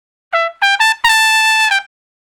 084 Trump Shuffle (E) 09.wav